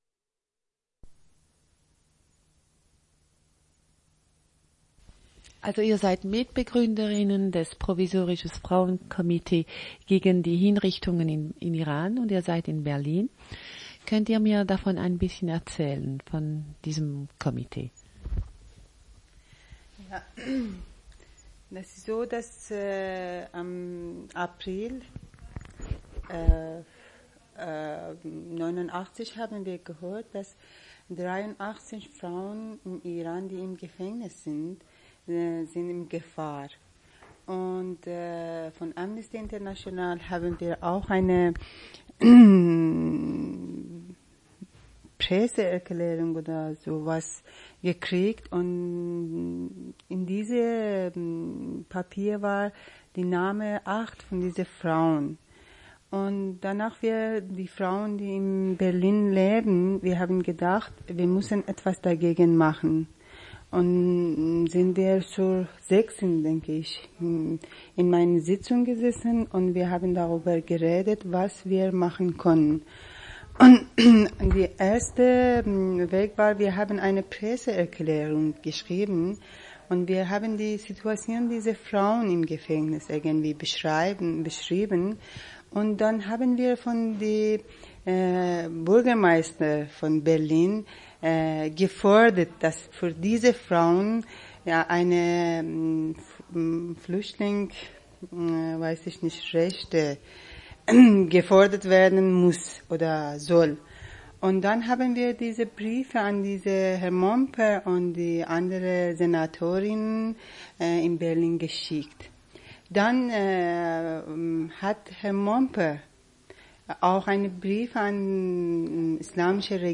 Entretien en allemand avec des femmes du Frauen Komitee gegen die Hinrichtungen in Iran.